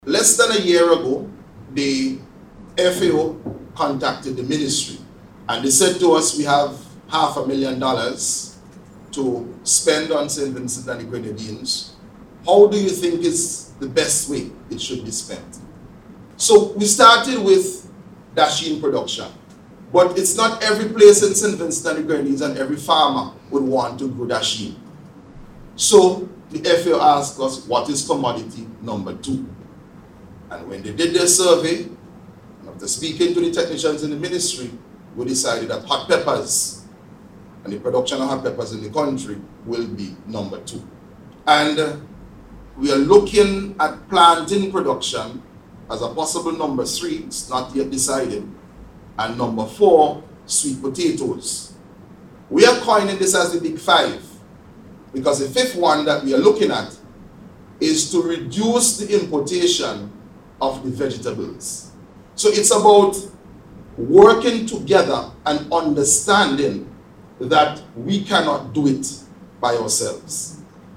During the opening ceremony, Minister of Agriculture, Saboto Caesar expressed thanks to the FAO for its continued support to St. Vincent and the Grenadines.